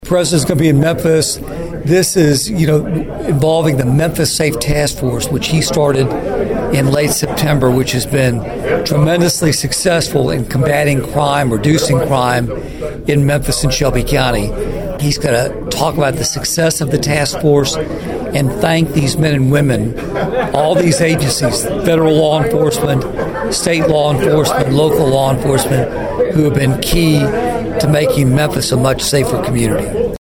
His first stop was at the Obion County Farm Bureau office, where he held a legislative update with individuals in agriculture and business.
During this stop, Congressman Kustoff told Thunderbolt News about a visit today in Memphis by President Trump.(AUDIO)